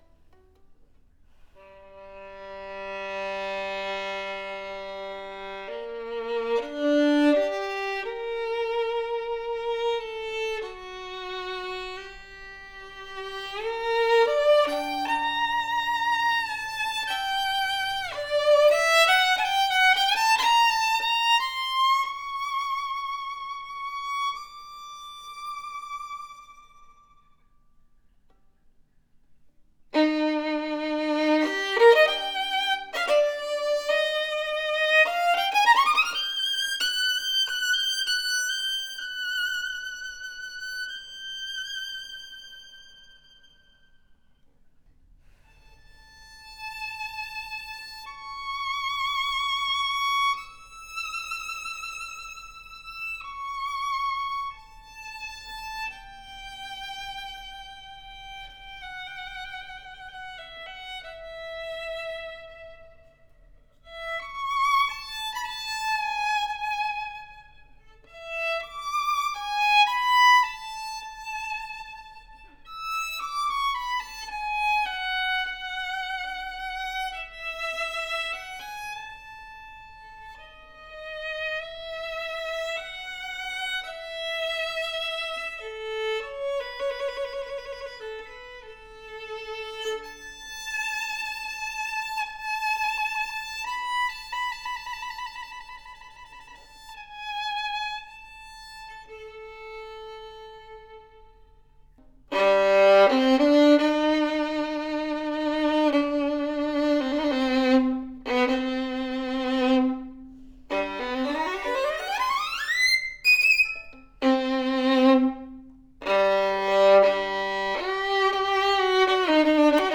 PHENOMENAL tone and workmanship on par with our more expensive violin!
Our best selling 1743 “Cannone” del Gesu, with the warm, resonant, and rich texture that serious players look for, a really warm and smooth sounding violin as the audio/video represents, with deep rich voice across all four strings.